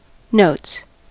notes: Wikimedia Commons US English Pronunciations
En-us-notes.WAV